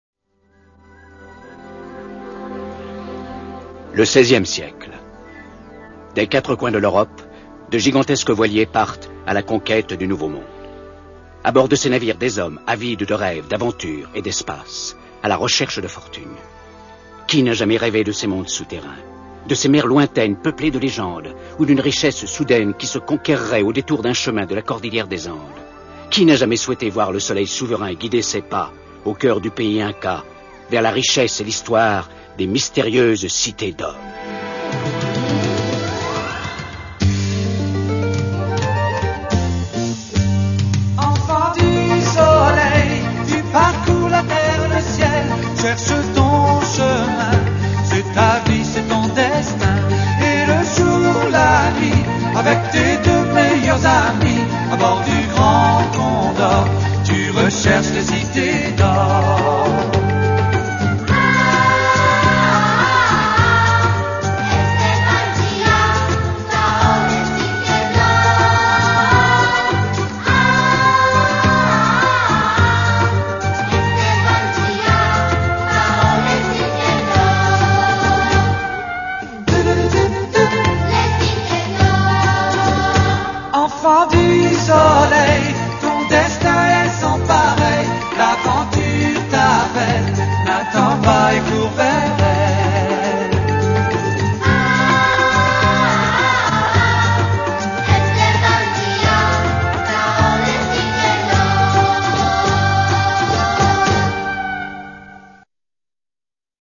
Version avec Paroles